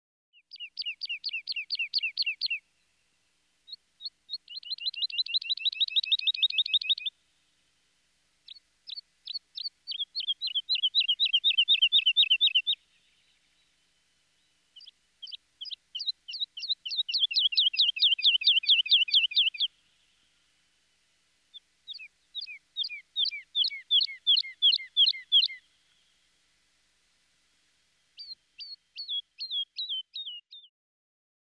Звуки пения птиц
Жаворонок утренний